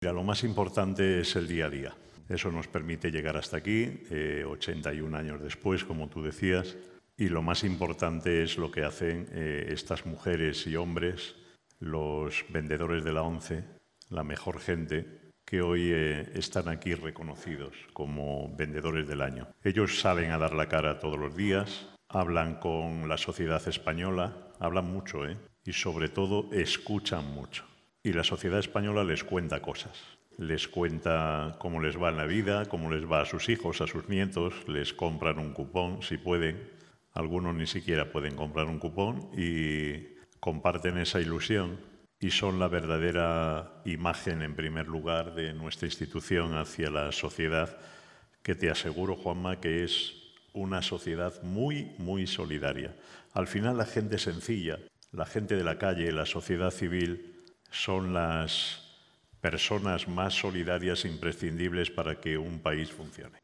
Homenaje a los mejores vendedores del 2019 con la celebración de la XIII Gala 'Buena Gente ONCE'
Acompañados por los máximos responsables de la entidad, el 7 de febrero, el Hotel Ilunion Pío XII de Madrid acogió la gala 'Buena Gente ONCE' en la que los mejores vendedores 2019 recogieron los galardones a su esfuerzo y dedicación.